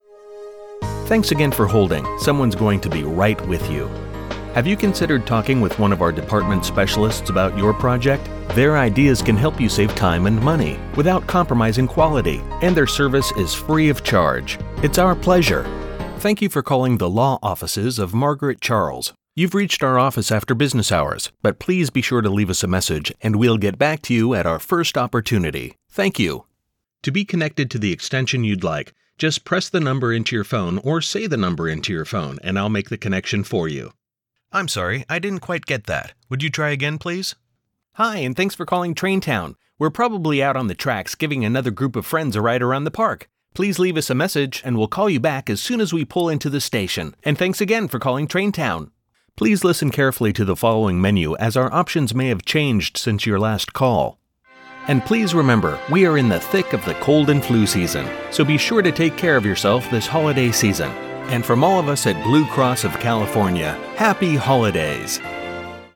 Sprechprobe: Sonstiges (Muttersprache):
Inviting and friendly, relaxed yet engaging, authoritative, energetic and sincere.